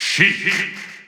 The announcer saying Sheik's name in English and Japanese releases of Super Smash Bros. 4 and Super Smash Bros. Ultimate.
Sheik_English_Announcer_SSB4-SSBU.wav